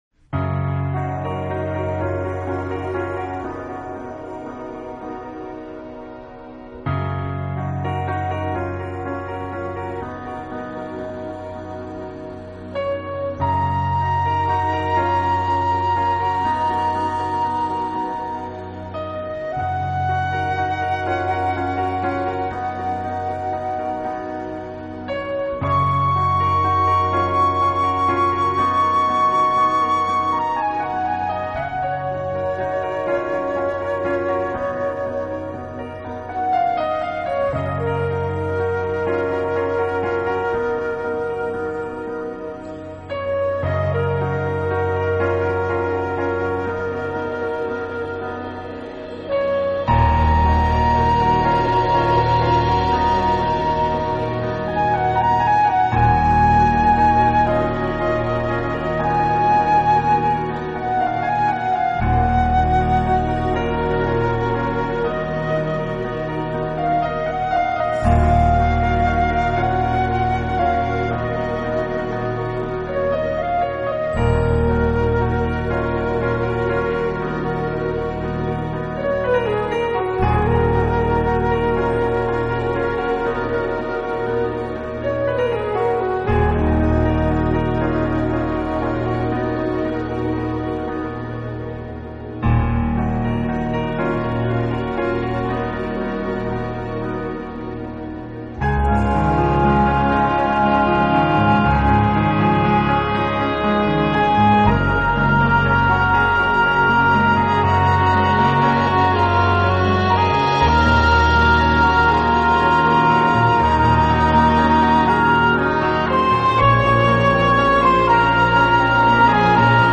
钢琴
音乐风格:Genre: New Age / Meditative
并不是纯钢琴，而是真正意义上的轻音乐。